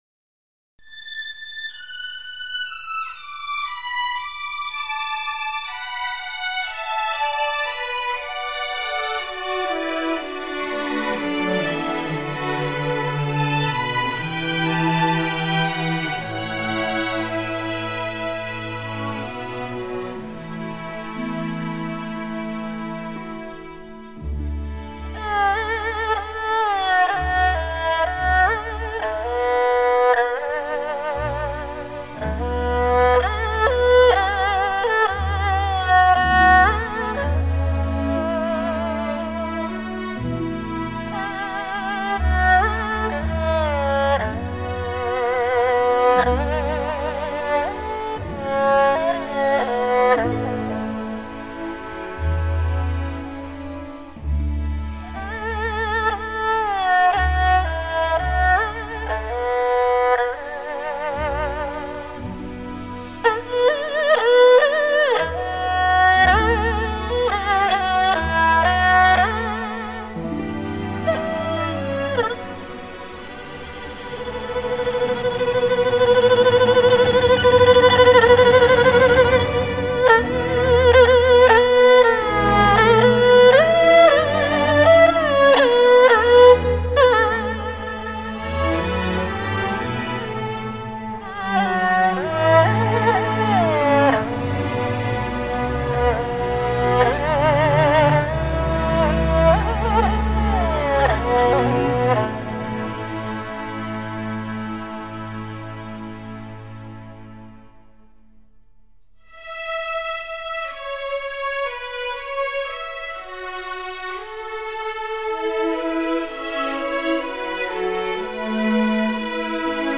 ★国际音响协会权威推荐的专业测试碟
低音二胡